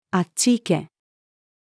あっち行け-female.mp3